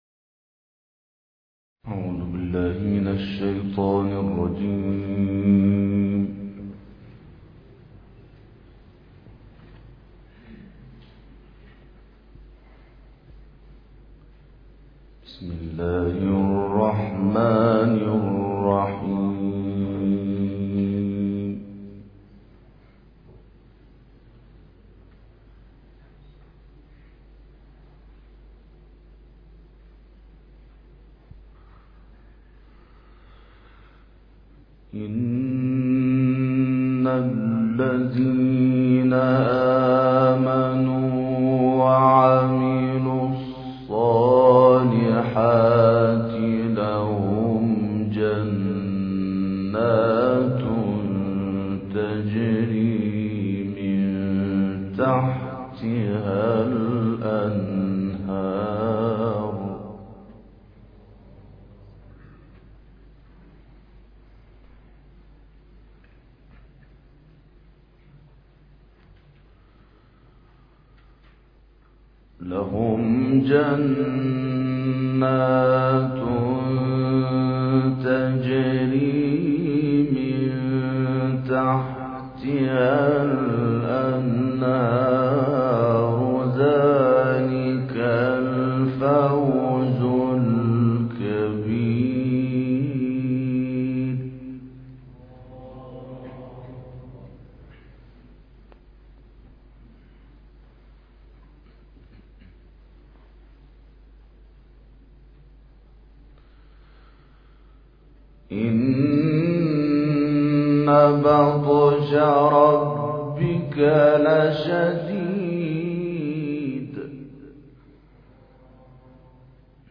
تلاوت آیاتی از سوره بروج